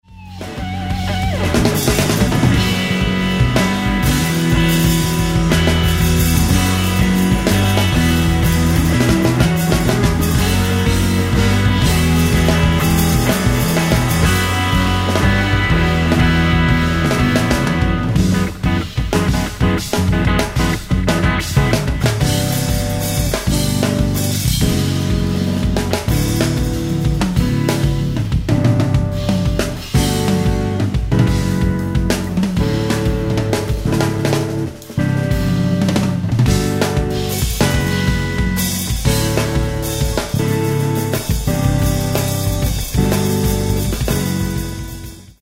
bassist and composer
on drums
on keyboards
on alto saxophone